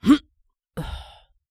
人声采集素材/男2刺客型/CK起身3.wav